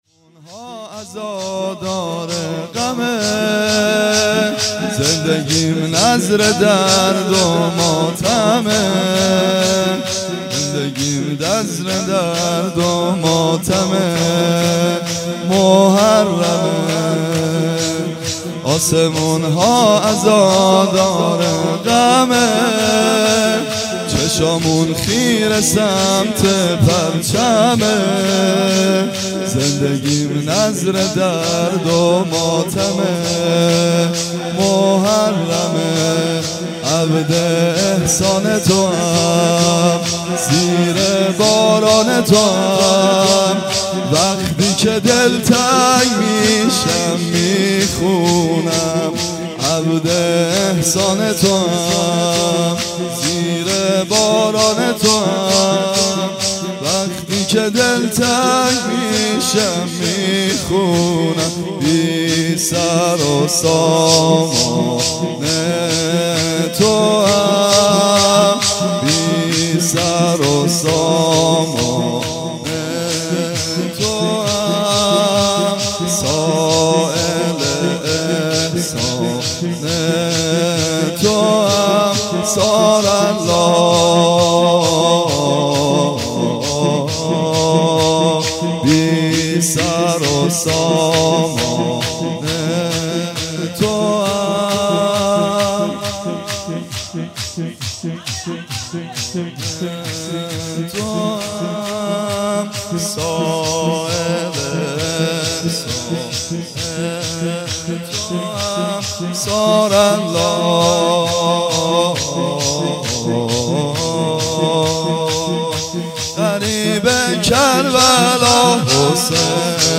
مداحی
به سبک سریال مختارنامه
به سبک تیتراژ پایانی سریال مختارنامه